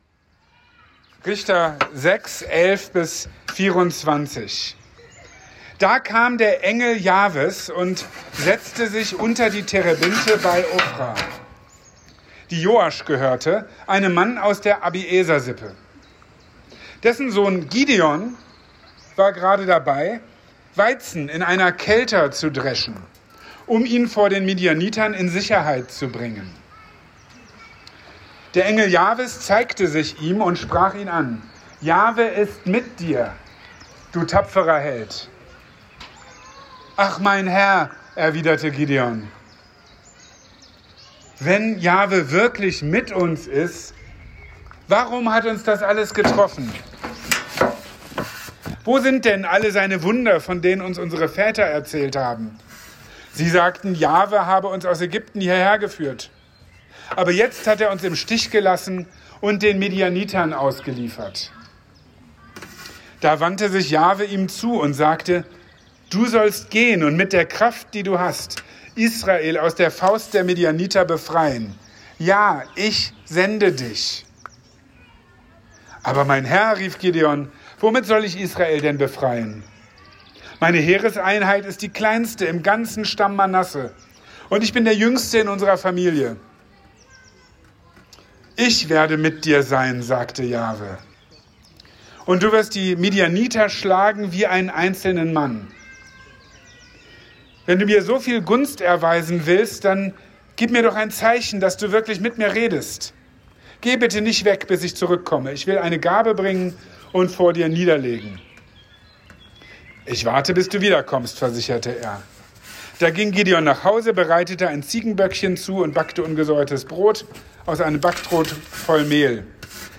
Mitten in einer chaotischen Krisenzeit kommt der Engel des Herrn zu einem verängstigten jungen Mann in seinem Versteck mit einer unglaublichen Zusage: „Der Herr ist mit dir!“ und mit eigentlich unmöglichen Auftrag: Sein Volk von den Feinden befreien. In seiner Predigt vom 9.